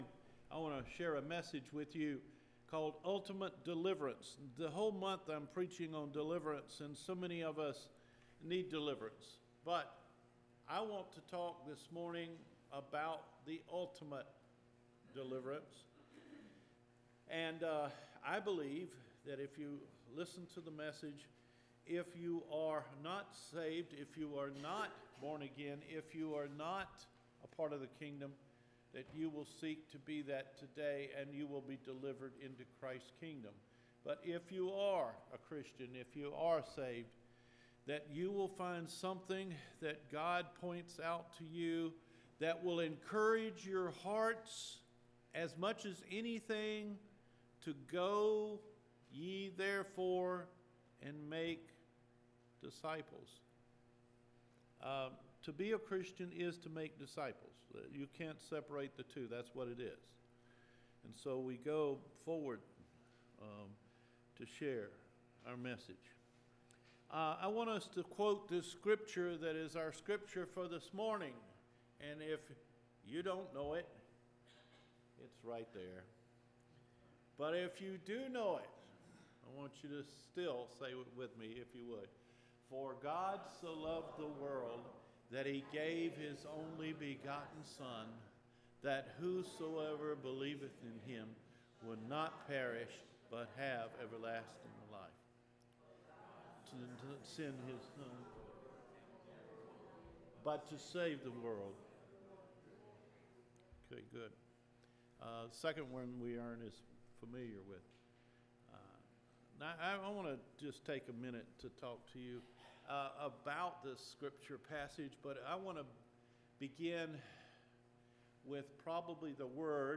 MAN’S ULTIMATE DELIVERANCE – NOVEMBER 17 SERMON